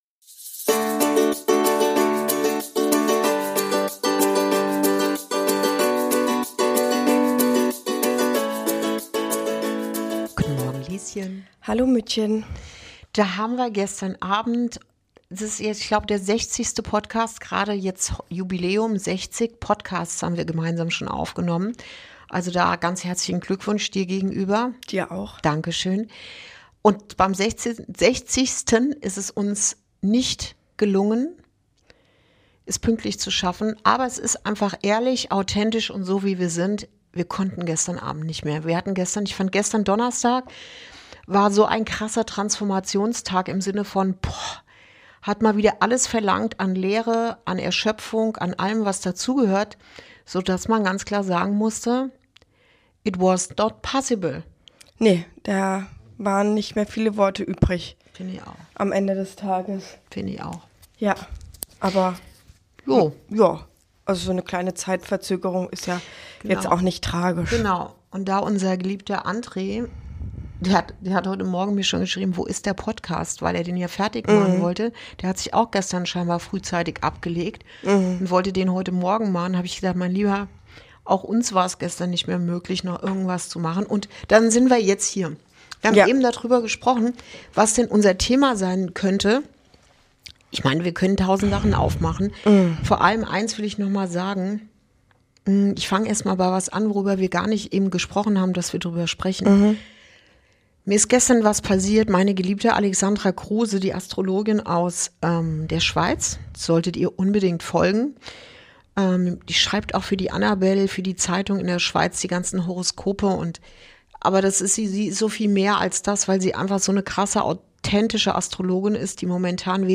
Eine ruhige, ehrliche Folge über Bewusstsein, Wahrnehmung und das Ankommen bei sich selbst.
Ein Gespräch zwischen Mutter und Tochter